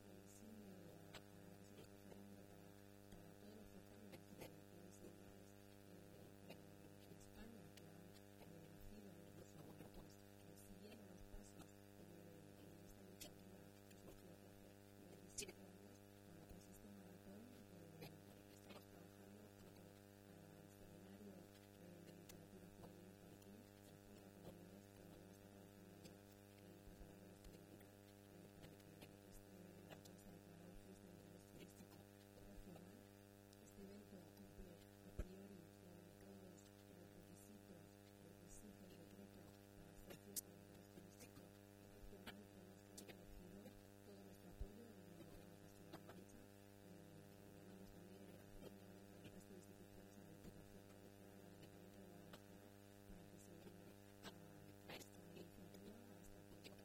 La directora general de Turismo, Comercio y Artesanía, Ana Isabel Fernández Samper, habla del apoyo del Gobierno regional a la declaración del Maratón de Cuentos de Guadalajara como Fiesta de Interés Turístico Regional.